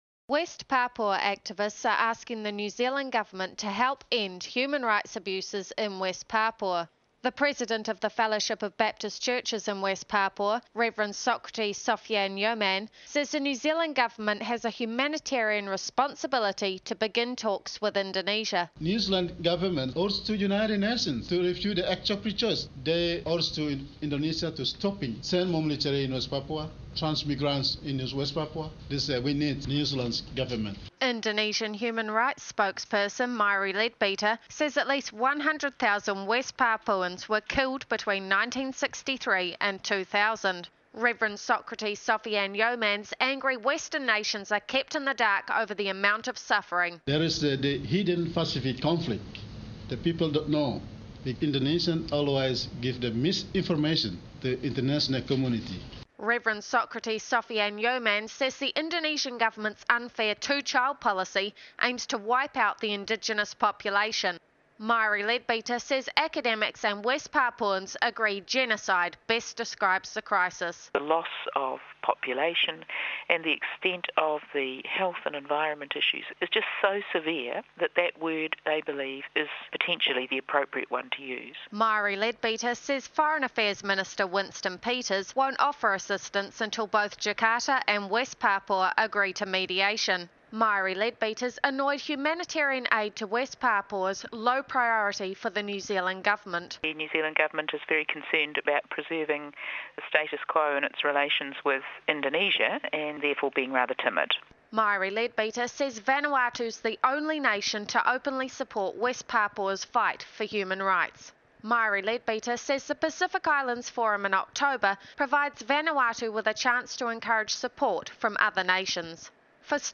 (Radio report from the West Papua conference at AUT University on August 18-19, 2006).